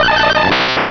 pokeemmo / sound / direct_sound_samples / cries / dugtrio.wav
dugtrio.wav